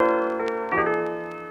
Keys_12.wav